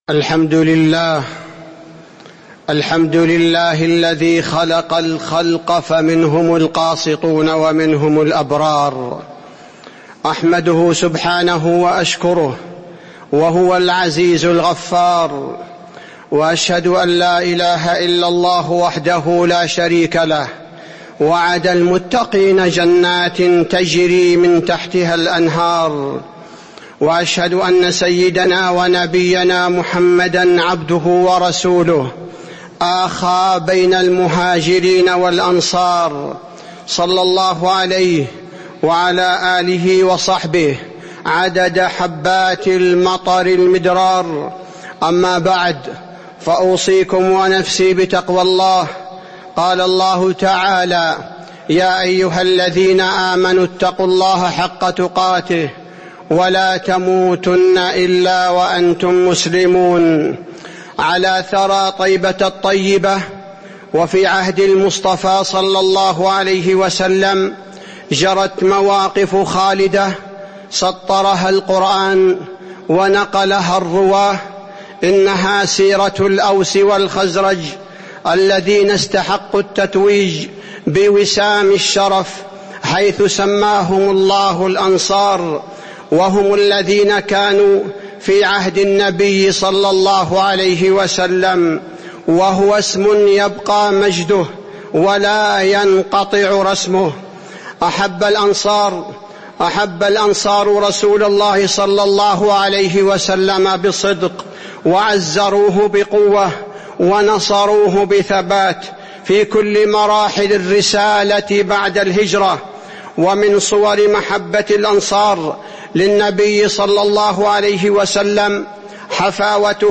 تاريخ النشر ٢ ذو القعدة ١٤٤٥ هـ المكان: المسجد النبوي الشيخ: فضيلة الشيخ عبدالباري الثبيتي فضيلة الشيخ عبدالباري الثبيتي من فضائل الأنصار